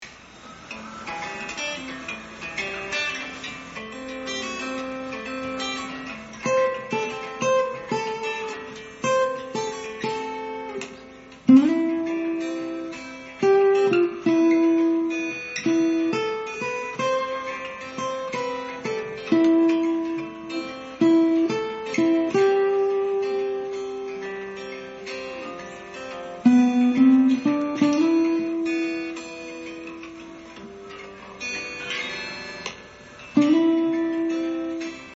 リードギター